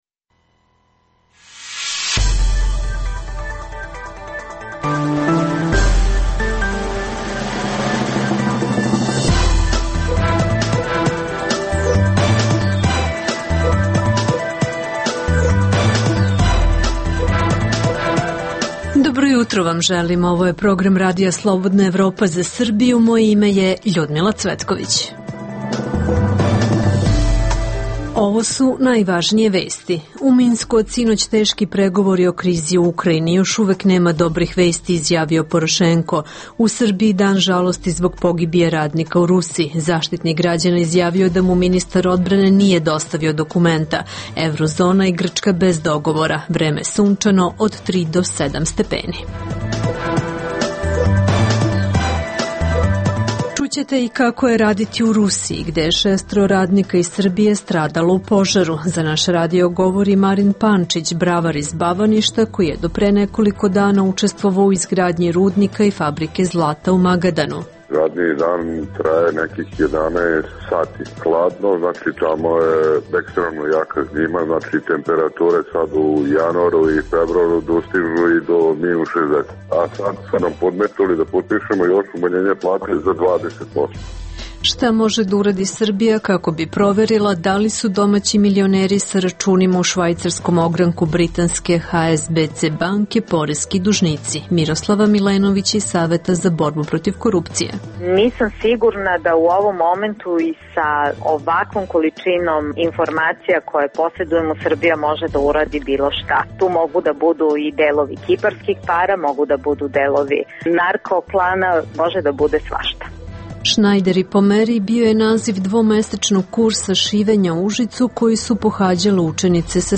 Čućete kako je raditi u Rusiji, gde je šestoro radnika iz Srbije stradalo u požaru. Za RSE govore radnici koji su radili od Sibira do Moskve.